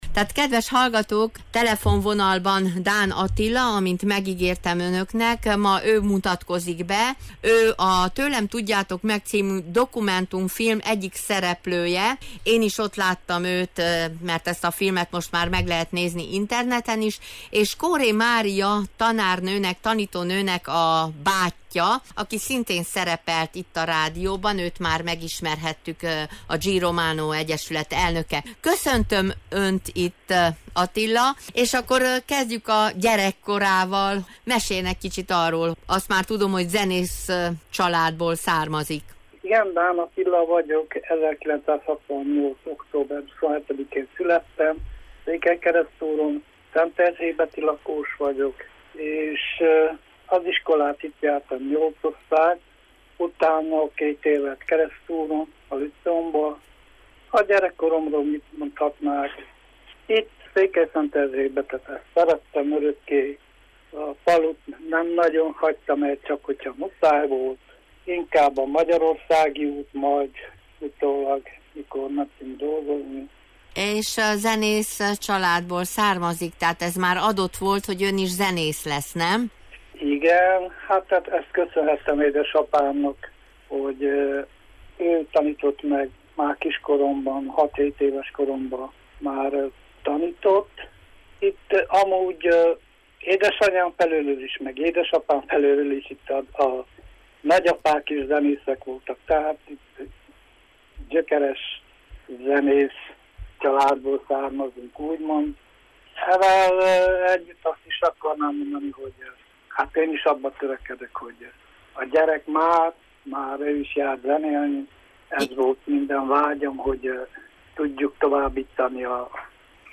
Mint hallani fogjuk, interjúalanyomat megkértem, küldjön pár felvételt, hogy a beszélgetést a saját zenekarának muzsikájával illusztrálhassuk.